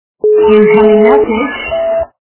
» Звуки » звуки для СМС » Женский голос - Message
При прослушивании Женский голос - Message качество понижено и присутствуют гудки.
Звук Женский голос - Message